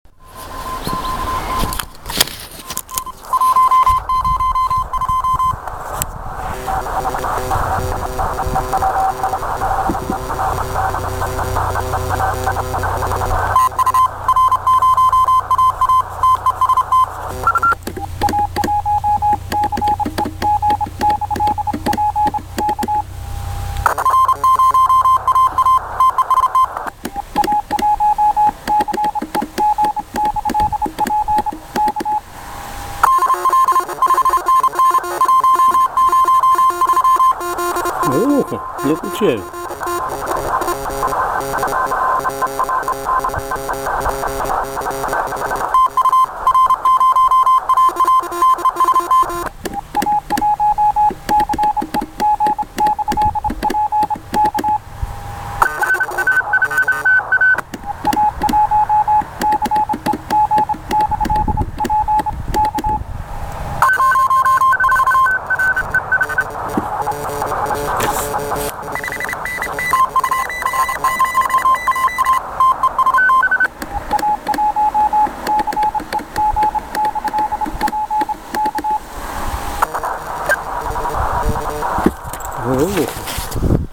ТЕМА: QSO на самоделках
Выходил в поля- леса поработать честными 5-ю ваттами в тесте "Kulikovo Polye Contest".